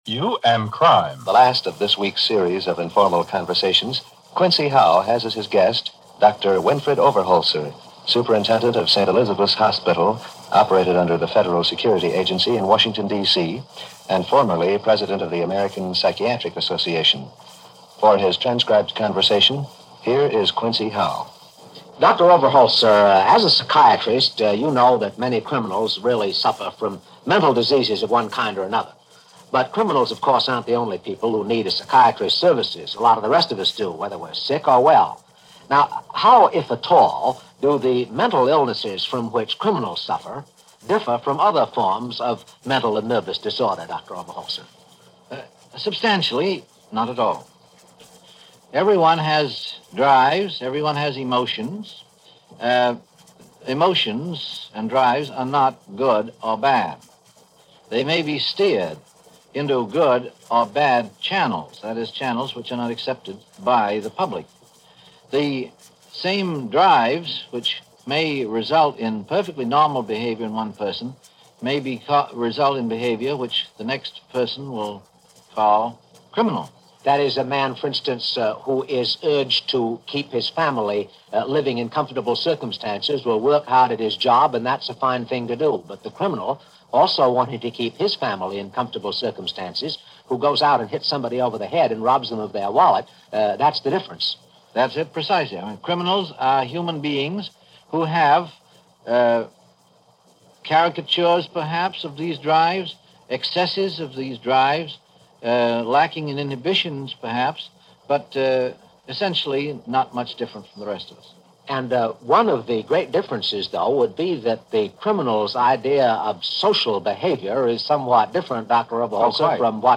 You And . . . Crime - 1949 -Past Daily After Hours Reference Room - CBS Radio Netword "You And . ." series - December 16, 1949